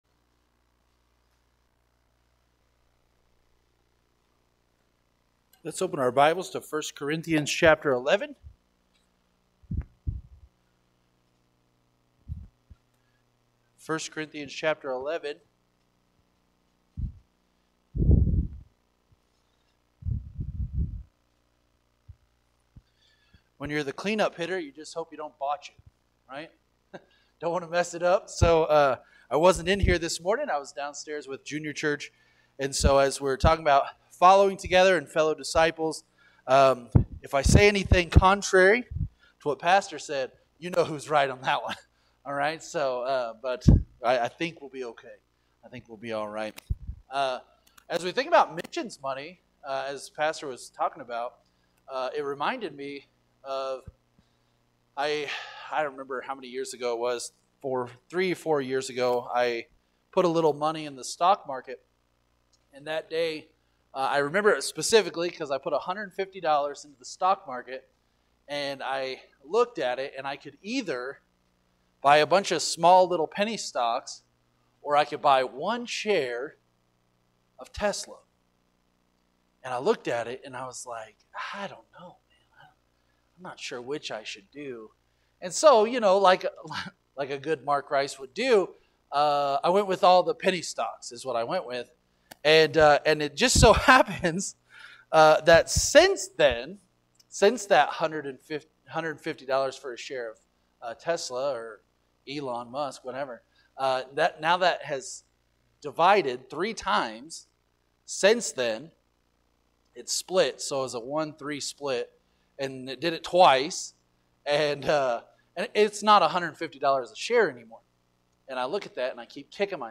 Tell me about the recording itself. Following Together, PM Service – Central Baptist Church